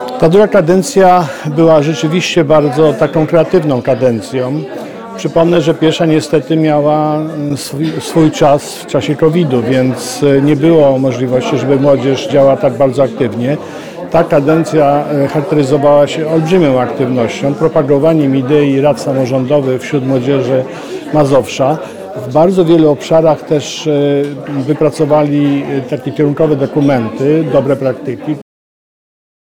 Mówi Marszałek Województwa Mazowieckiego Adam Struzik.